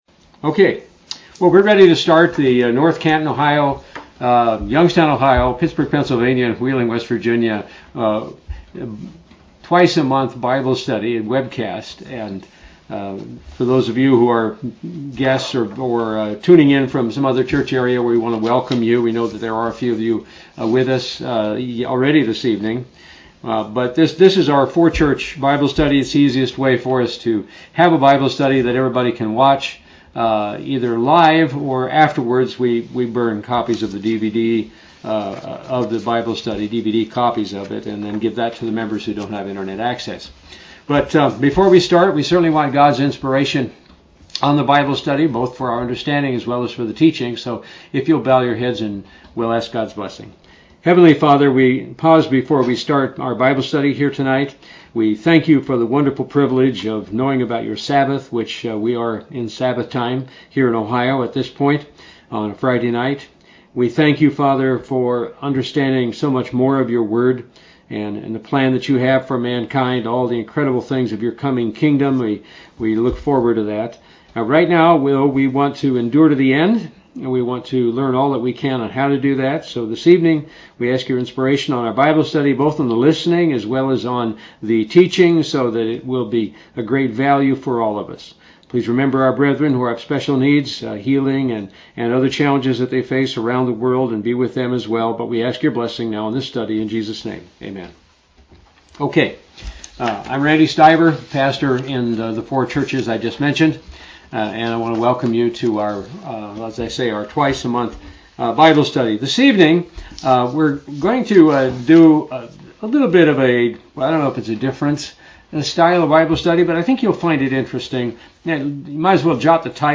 Print A Bible Study: We are all familiar with the armor of God.